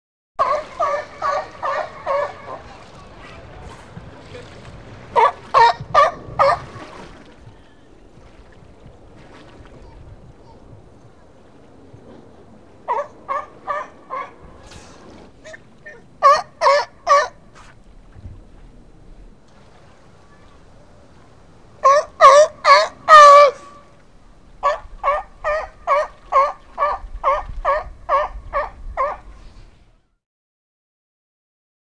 L’otarie | Université populaire de la biosphère
elle bêle, grogne, rugit
otarie.mp3